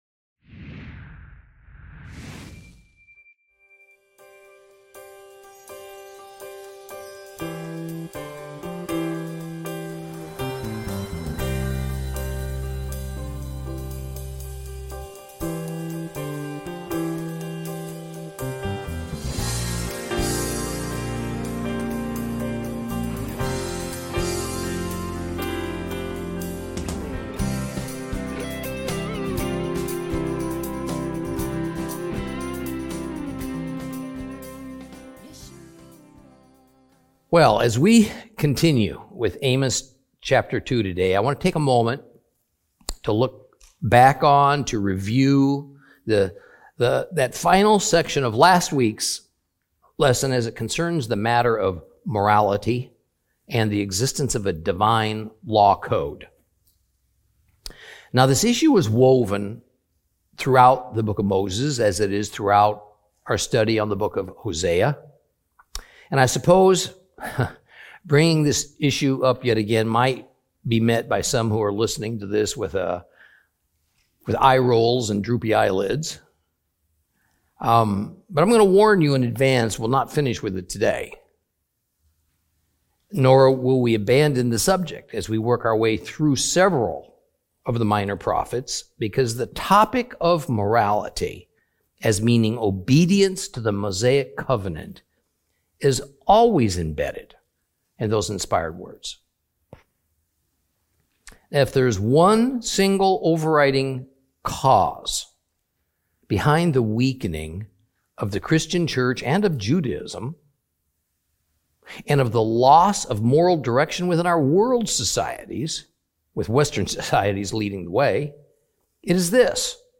Teaching from the book of Amos, Lesson 4 Chapter 2 continued.